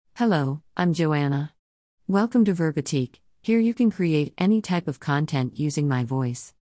JoannaFemale US English AI voice
Joanna is a female AI voice for US English.
Voice sample
Listen to Joanna's female US English voice.
Female